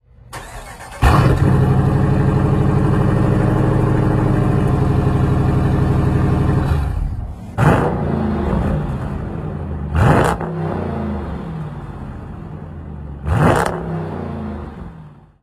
5.7L HEMI V8
Vychutnejte si tradiční americký bublavý projev a rychlý nástup kroutícího momentu v nízkých otáčkách.
NASTARTUJTE STISKNUTÍM
Temperamentní zvuk
Volitelný balíček TOW N GO modelu R/T přidává laděný výfuk, tvrdší podvozek a sportovní jízdní režimy po vzoru vrcholových verzí SRT.
dodge-durango-rt-tow-n-go.mp3